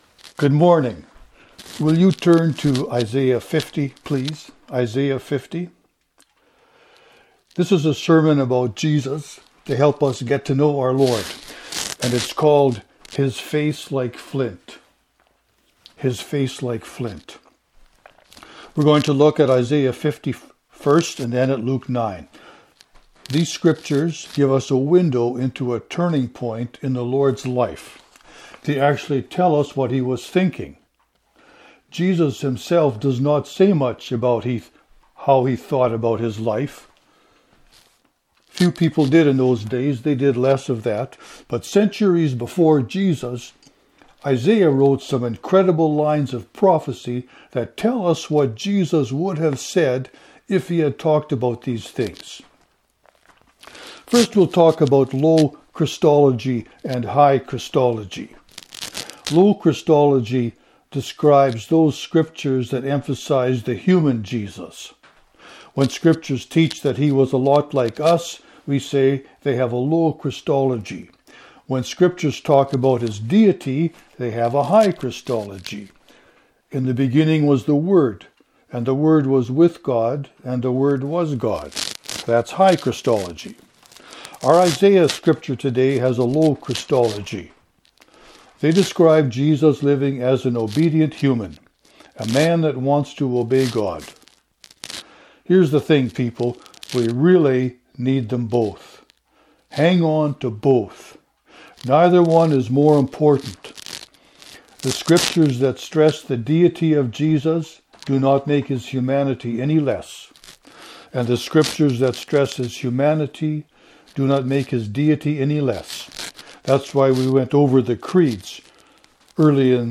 This is a sermon about Jesus, to help us know our Lord.